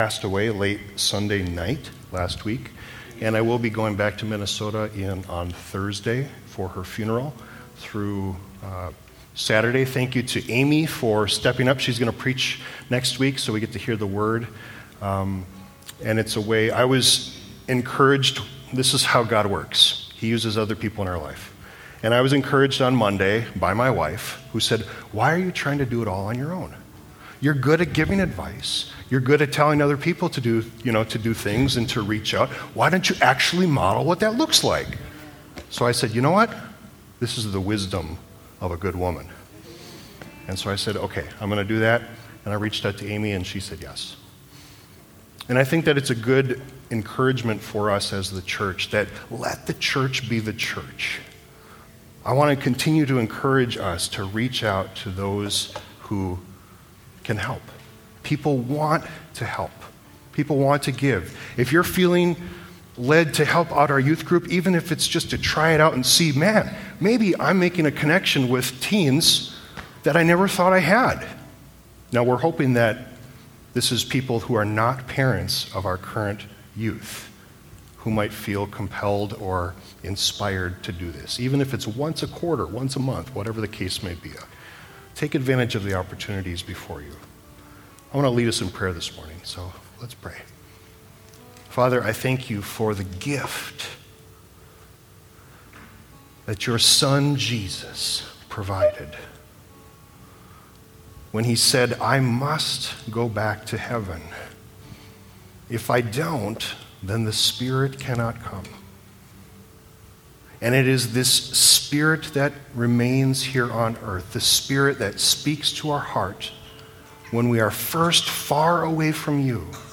New Life: To Encourage And Build Up – Edgewater Covenant Church